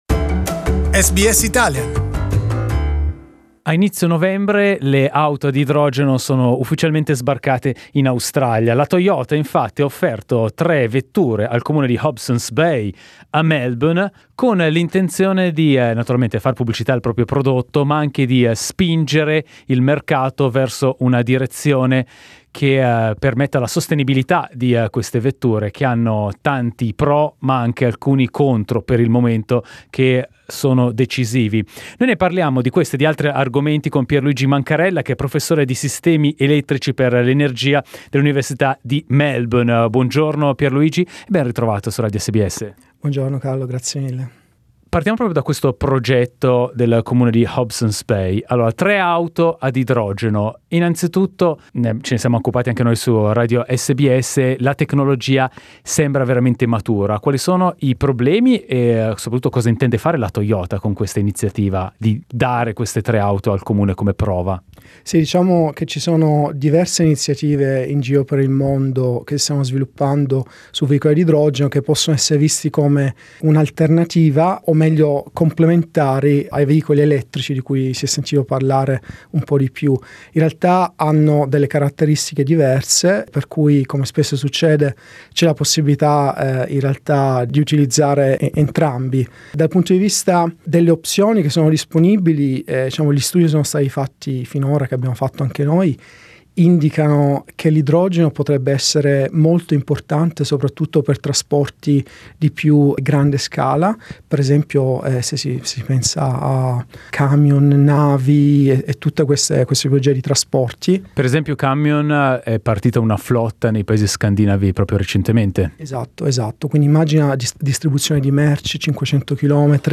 Listen to SBS Italian interview